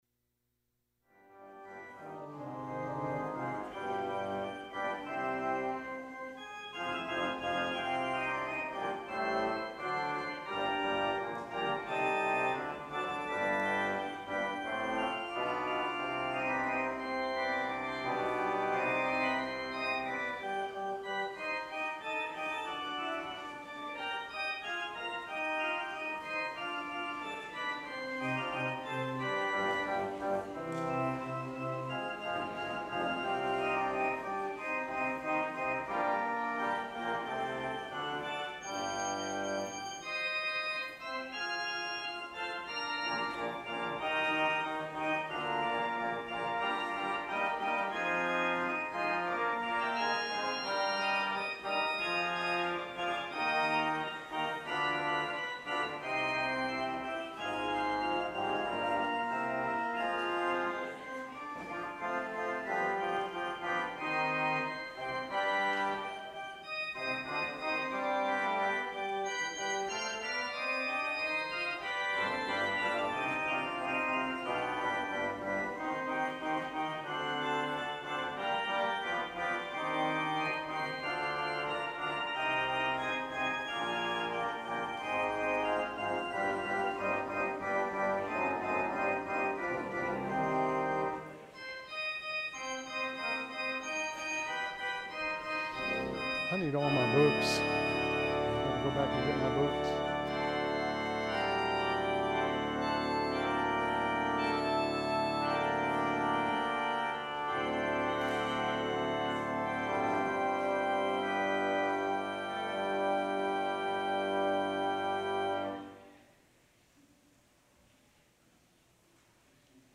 Traditional Sermon